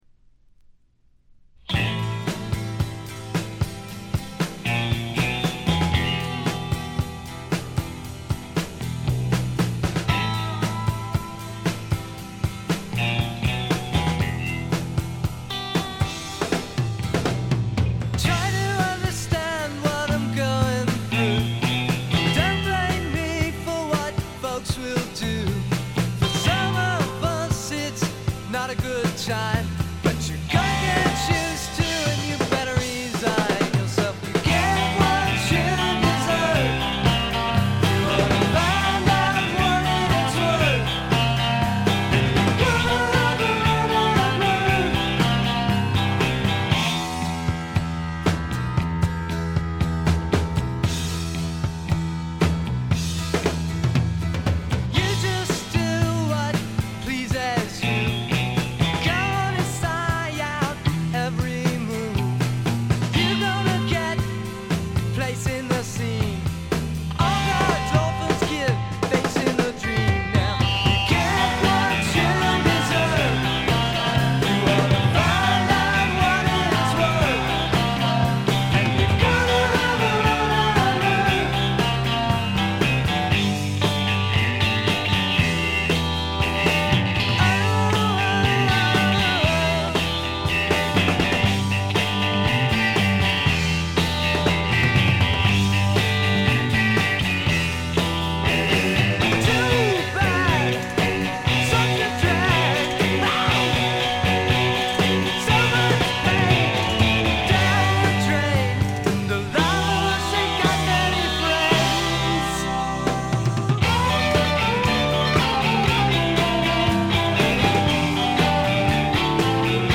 散発的なプツ音が3ヶ所ほど（ほとんど気付かないレベル）。
試聴曲は現品からの取り込み音源です。